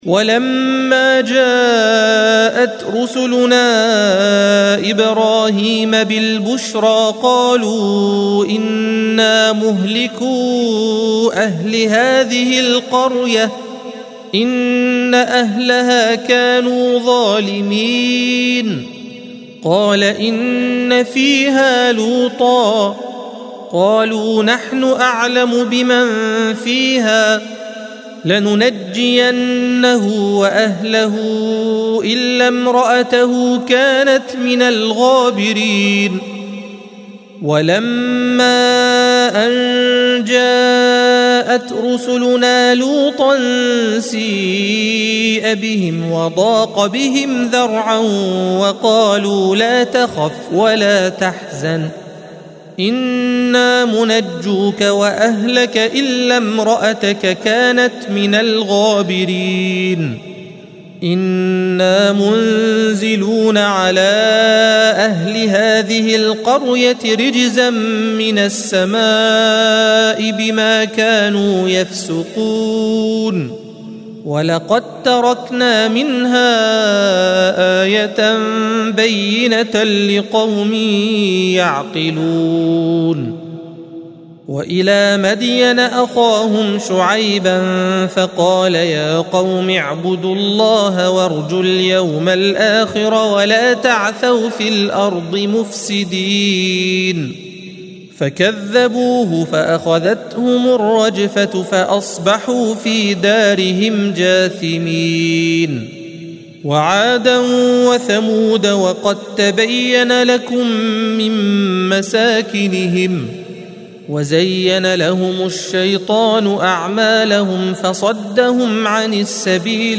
الصفحة 400 - القارئ